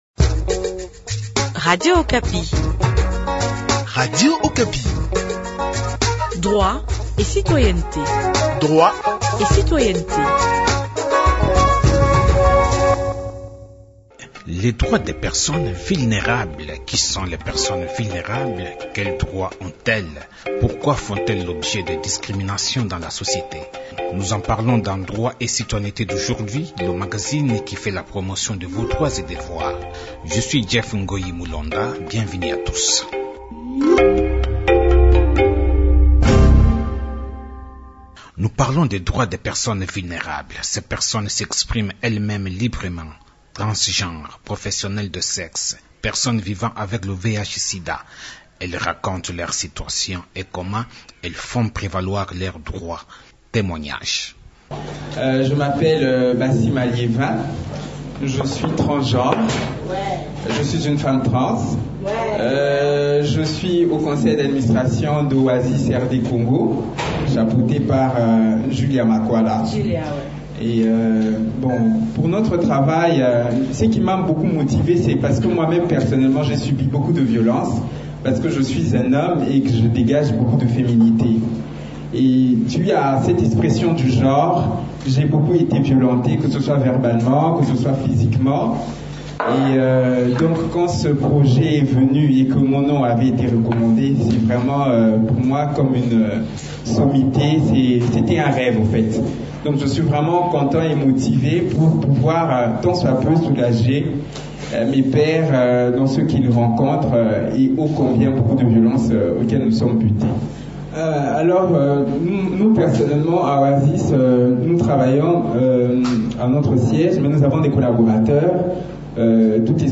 D’où, à travers une tribune d’expression populaire du Renadef, Réseau national du développement de la femme, elles plaident avec des parajuristes et avocat qui les accompagnent pour leur protection, pour l’application stricte de la loi y relative.